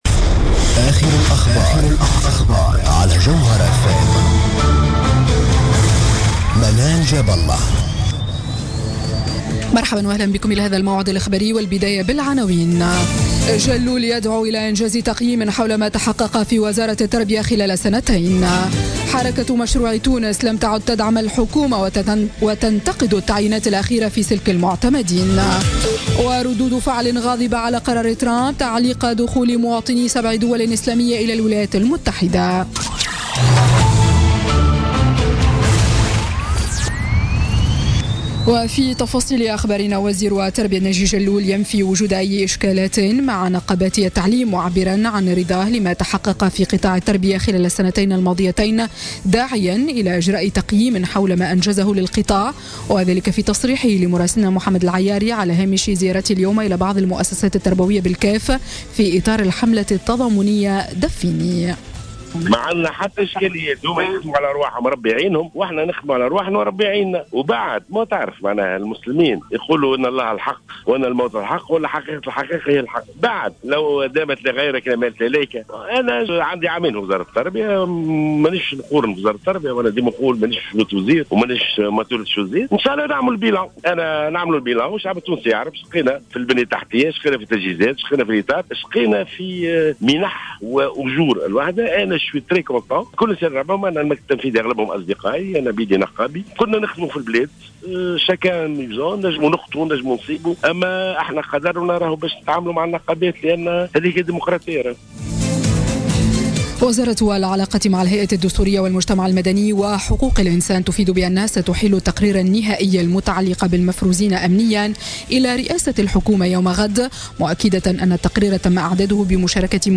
نشرة أخبار السابعة مساء ليوم الأحد 29 جانفي 2017